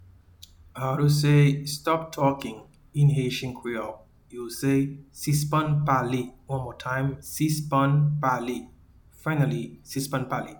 Pronunciation:
Stop-talking-in-Haitian-Creole-Sispann-pale-1.mp3